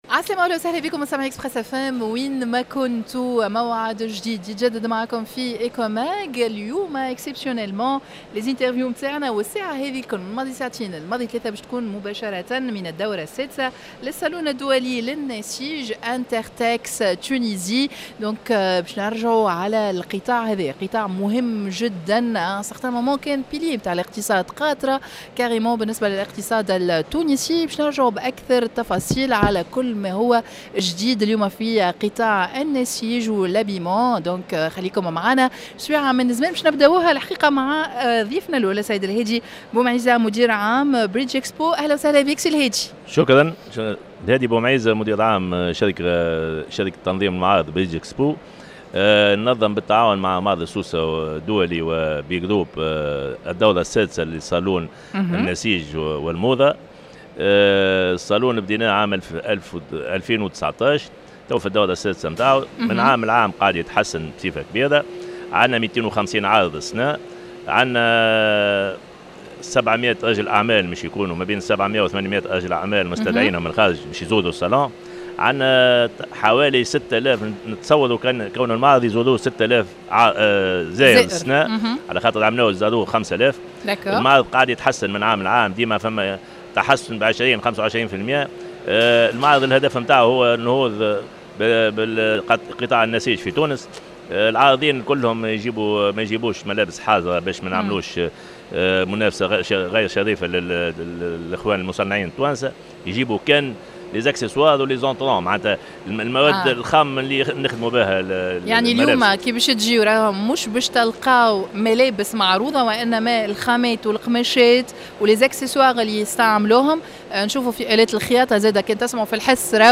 INTERTEX Tunisia dans un plateau spécial en direct de la foire internationale de Sousse.